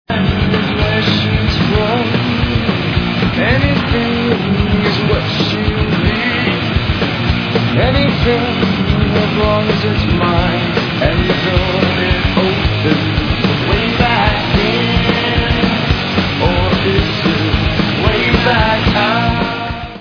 Rock/Hardcore